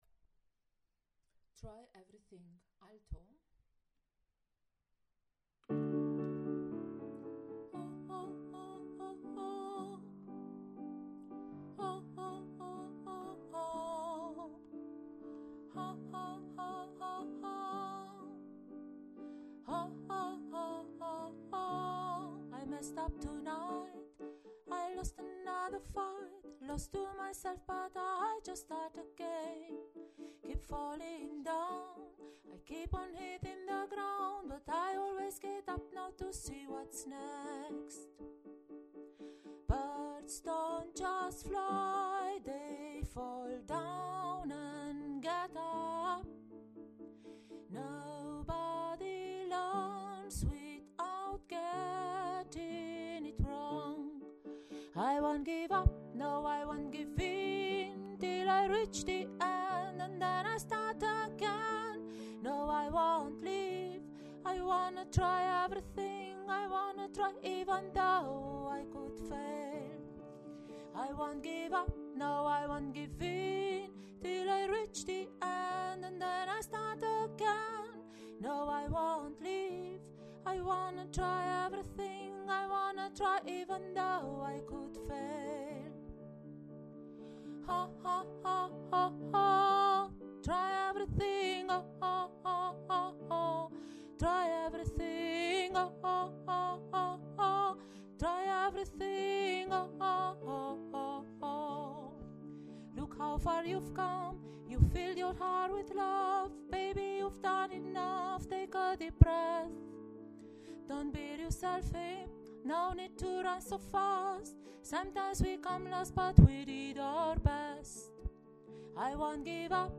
Try Everything – Alto